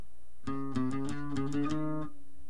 para bajosexto!!!!!